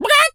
chicken_cluck_scream_02.wav